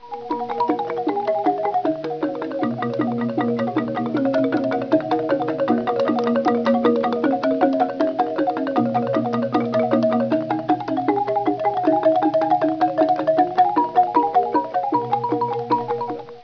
Bali, bambou (354 Kb)
Un gamelan est un ensemble instrumental traditionnel indonésien composé principalement de percussions : gongs, métallophones, xylophones, tambours, cymbales, flûtes.